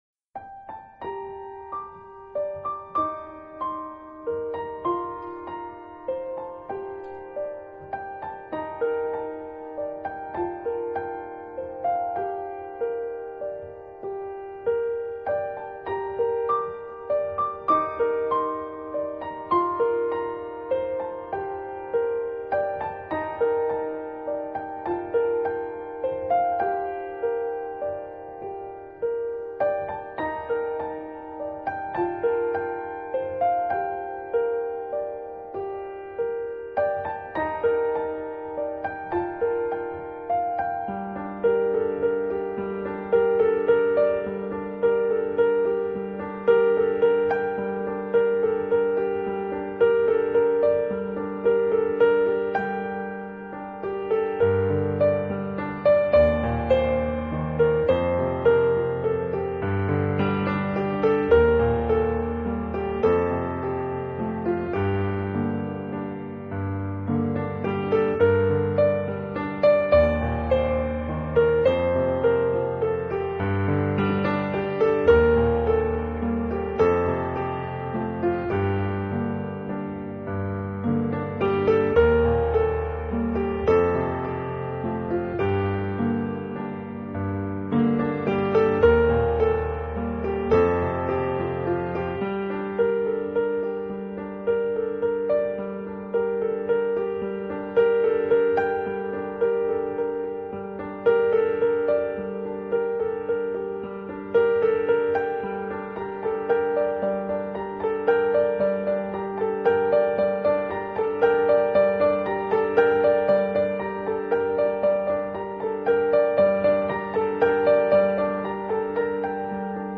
【柔美钢琴】